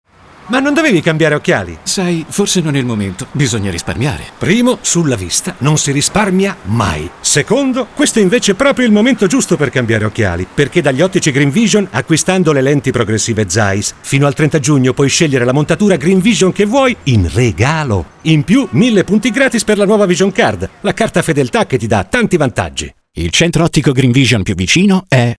Zeiss - campagna radiofonica
Campagna Zeiss - Radio.mp3